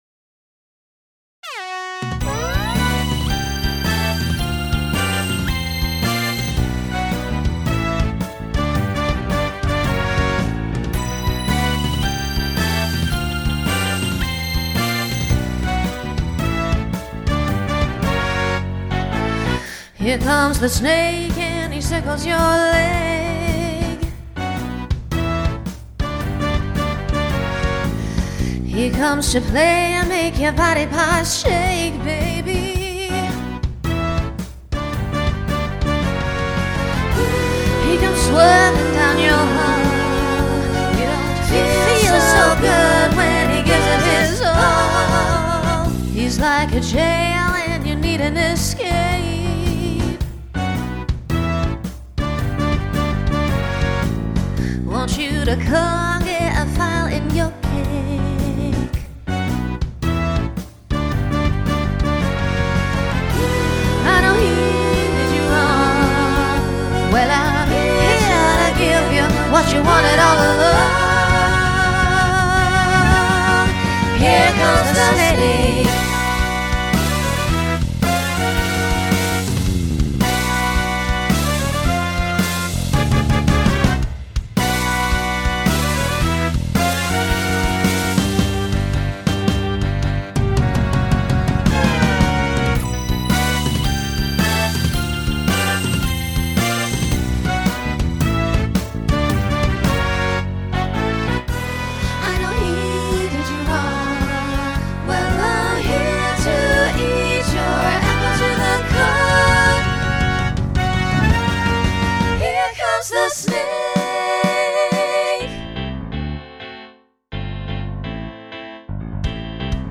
Starts SSA, then TTB, then SATB.
Genre Pop/Dance , Swing/Jazz
Voicing Mixed